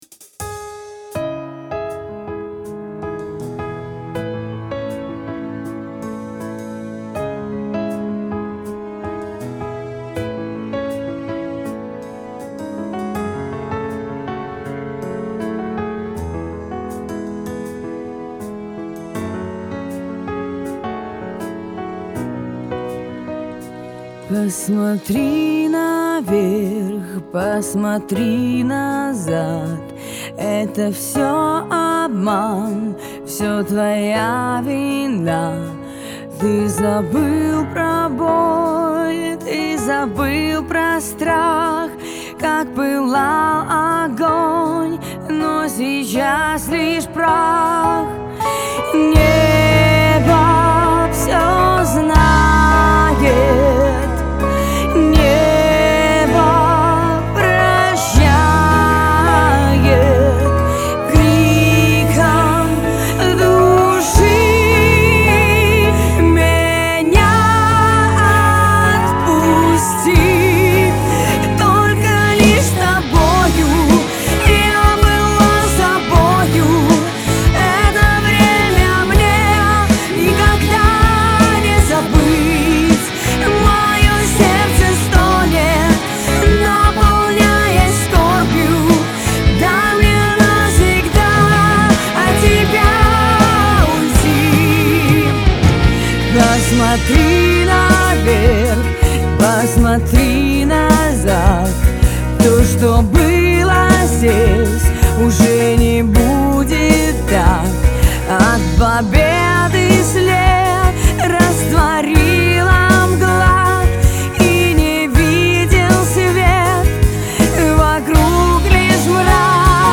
Вокал
Клавиши
Барабаны
Бас гитара
Гитара